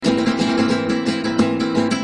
Flamenco guitars soundbank 3
Free MP3 flamenco guitars loops & sounds 3